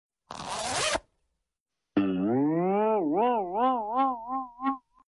ziip boin boing boing#fy sound effects free download